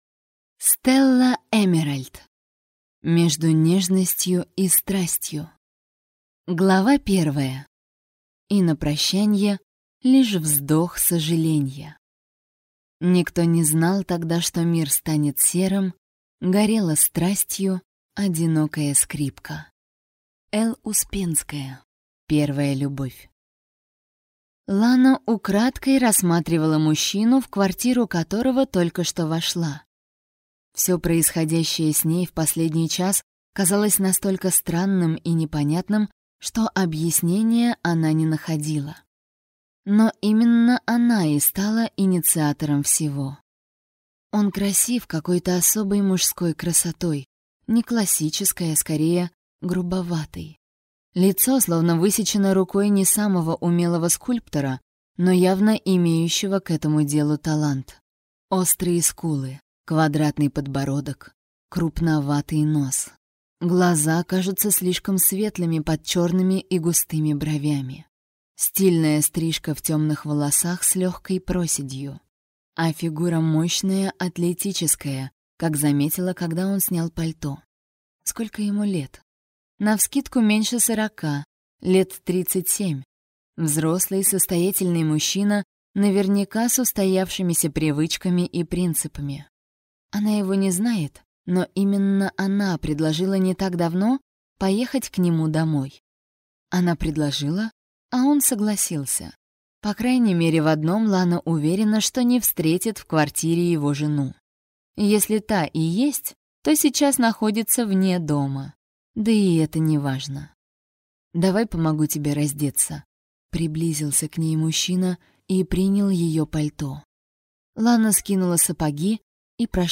Аудиокнига Между нежностью и страстью | Библиотека аудиокниг